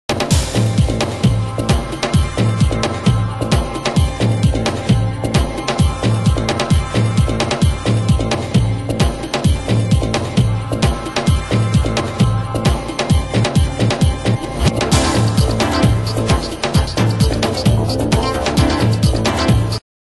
○テクノトラック〜ダウンビートまで、独自の美意識で構成された傑作アルバム！